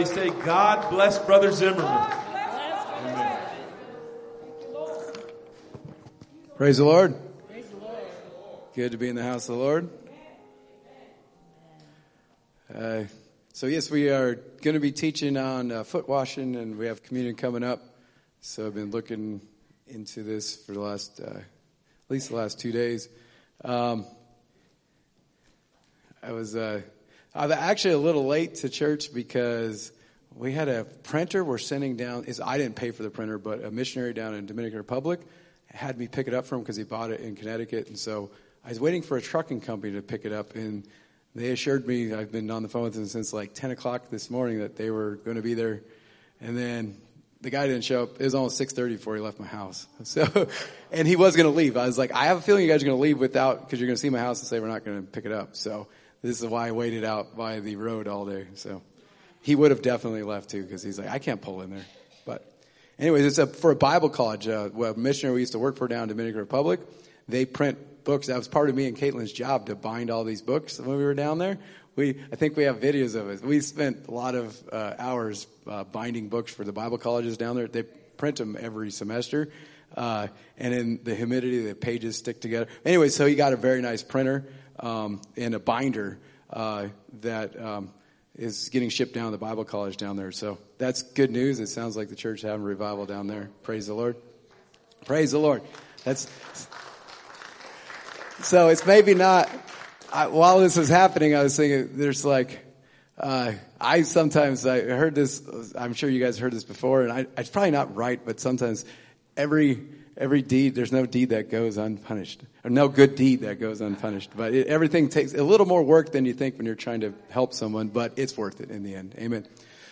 Sermons | Apostolic Lighthouse UPC